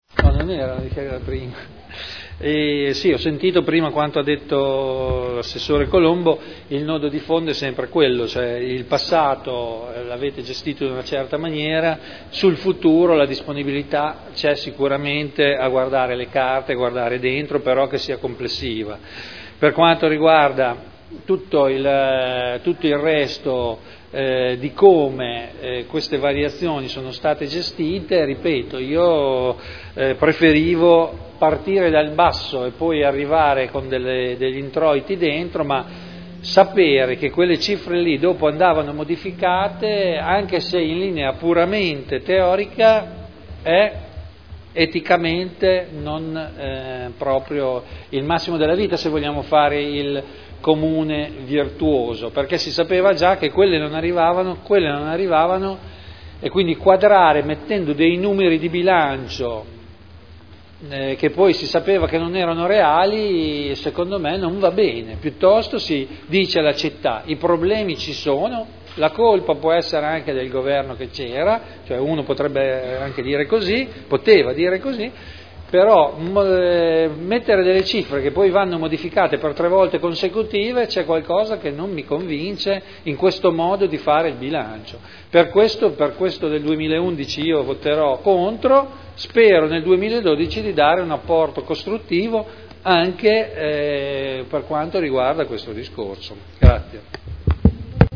Seduta del 28 novembre Proposta di deliberazione Bilancio di previsione 2011 - Bilancio pluriennale 2011-2013 - Programma triennale dei lavori pubblici 2011-2013 - Assestamento - Variazione di bilancio n. 3 Dichiarazioni di voto